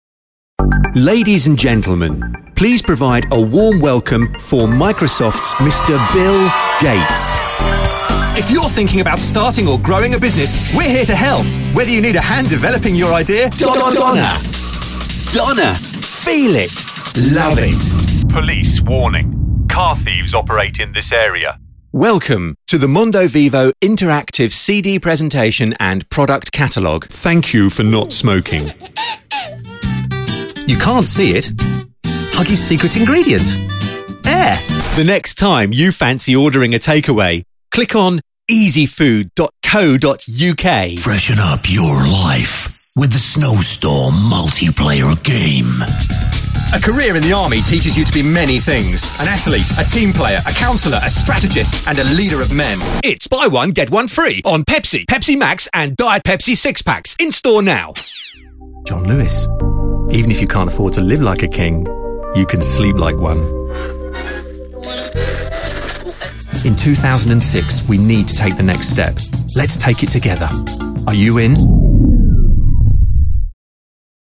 I specialise in cost effective, Received Pronunciation (accent-free), voice overs for film, television and radio.
britisch
Sprechprobe: Industrie (Muttersprache):